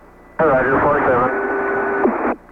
capcom_orbit_misc_19.wav